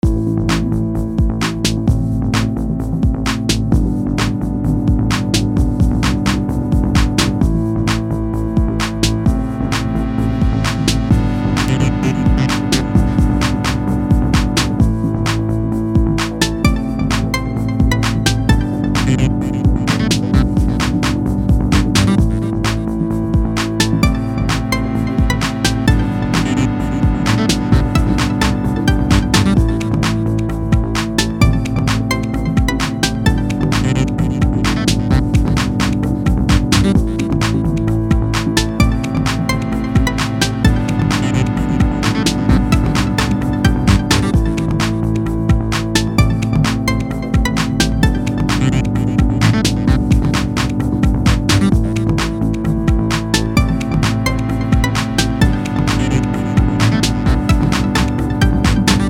Simple, RPG-style Loop.